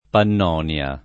[ pann 0 n L a ]